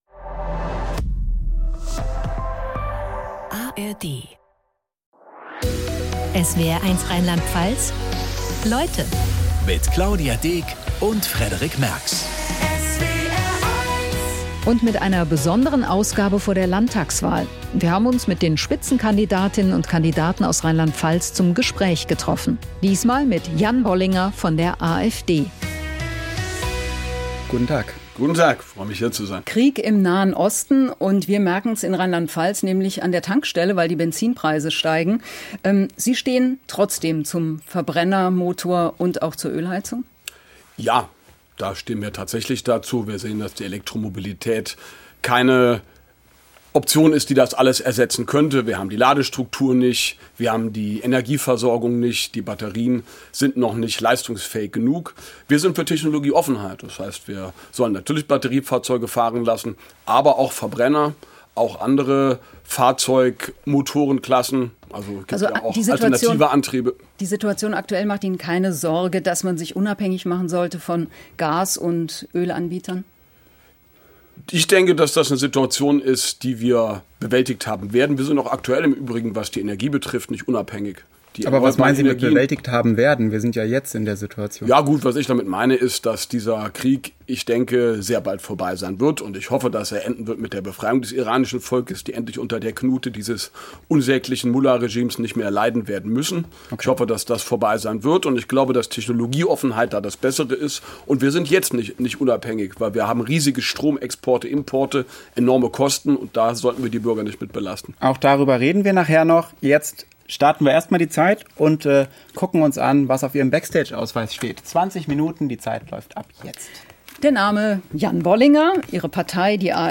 Anlässlich der Landtagswahl 2026 in Rheinland-Pfalz sendet der SWR multimediale Interviews mit den Spitzenkandidatinnen und -kandidaten von SPD, CDU, Grünen, AfD, Freien Wählern, Linken und FDP.
In dieser Folge zu Gast: Jan Bollinger von der AfD.